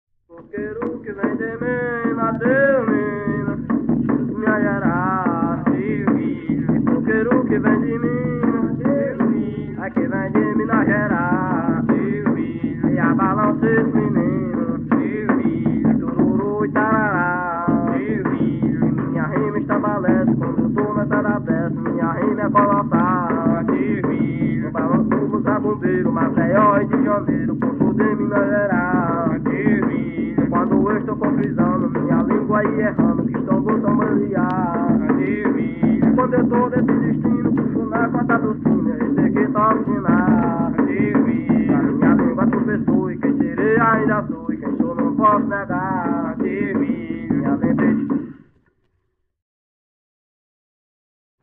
Coco- “”Adeus Minas Gerais”” - Acervos - Centro Cultural São Paulo